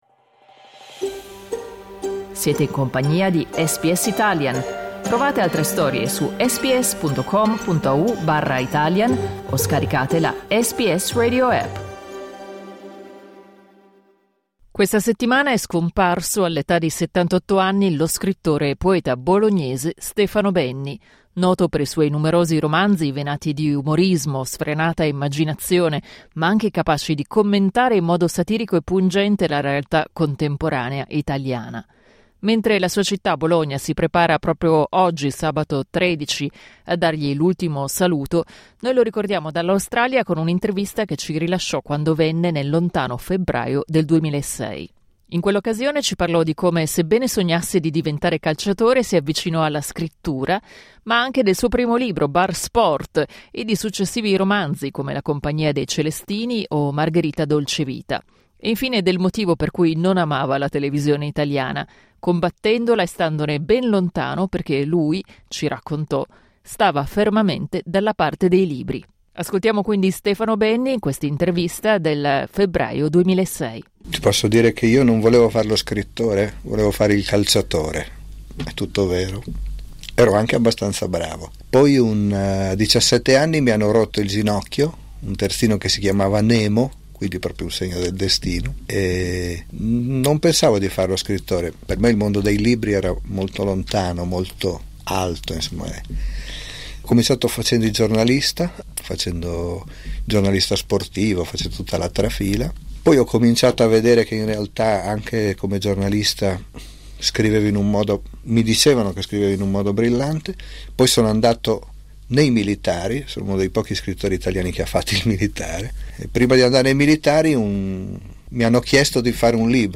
Dai nostri archivi, un'intervista a Stefano Benni del 2006
È scomparso nei giorni scorsi, all'età di 78 anni, lo scrittore e poeta bolognese Stefano Benni. Lo ricordiamo con un'intervista che ci rilasciò quando venne a Melbourne nel lontano febbraio del 2006.